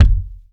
impact_deep_thud_bounce_09.wav